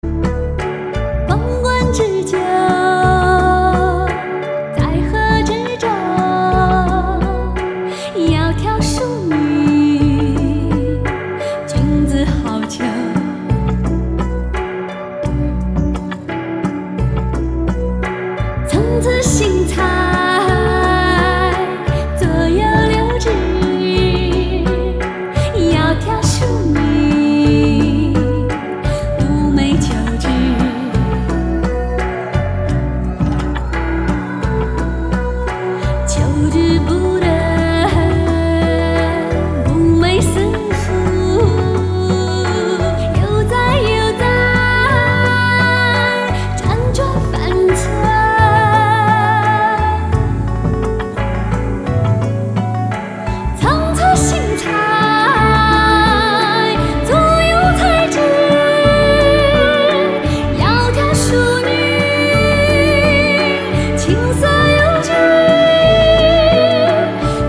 中國音樂、發燒天碟